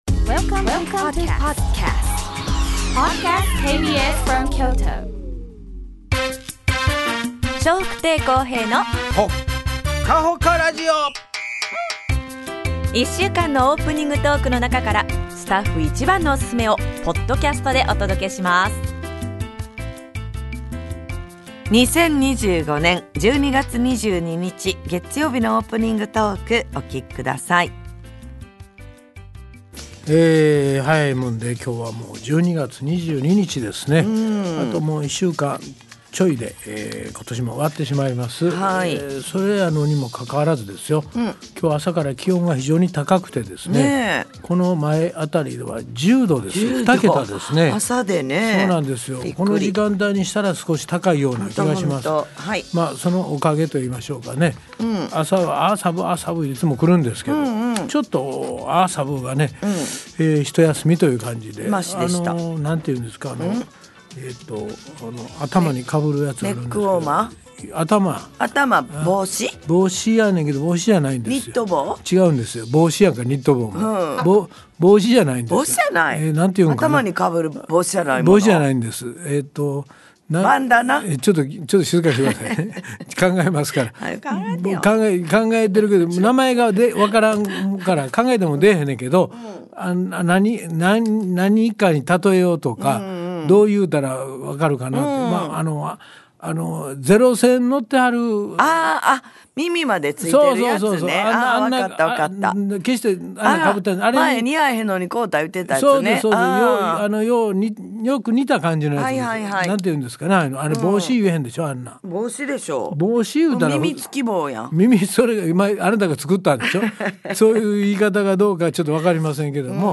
2025年12月22日のオープニングトーク
それではどうぞお二人のやりとりをお楽しみ下さい♪